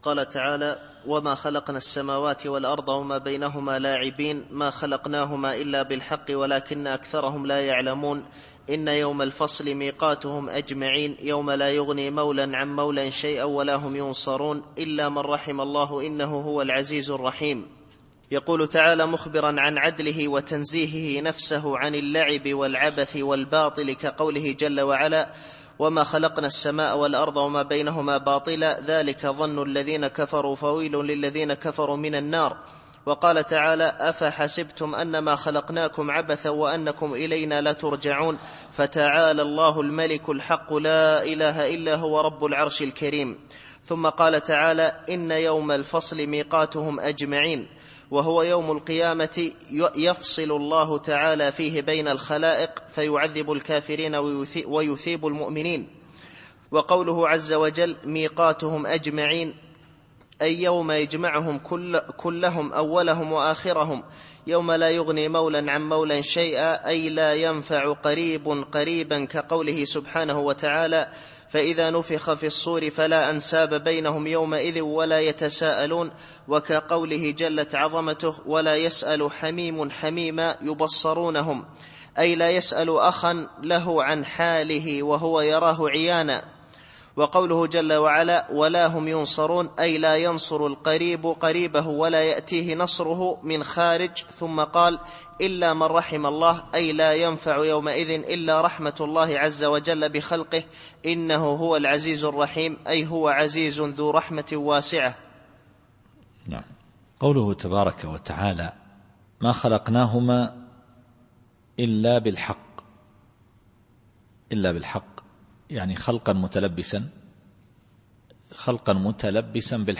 التفسير الصوتي [الدخان / 38]